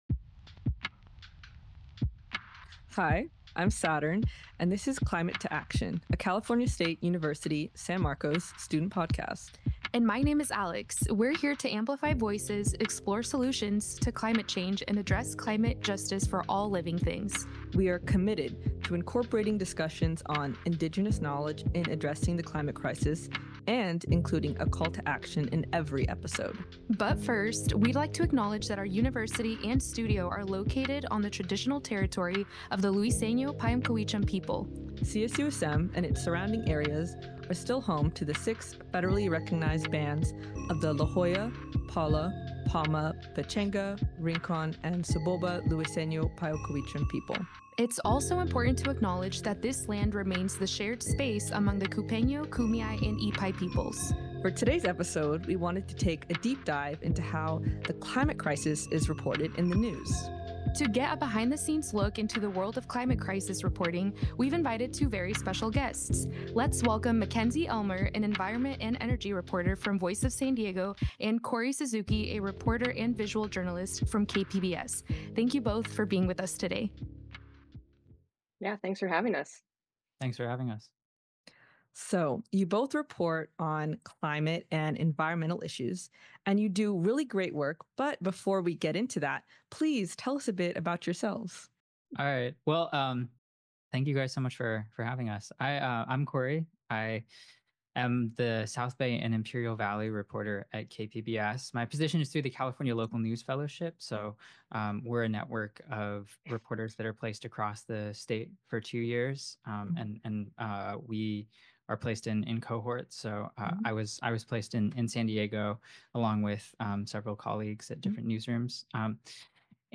Climate to Action is produced at the CSUSM Inspiration Studios.